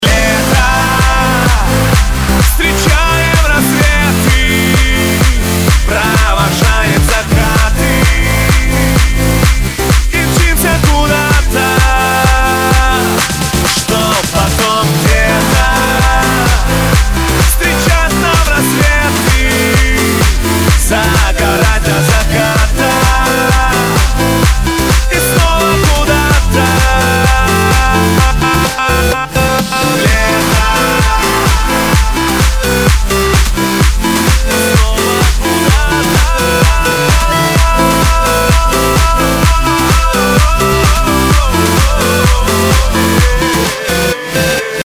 • Качество: 192, Stereo
Новый танцевальный летний хитяра!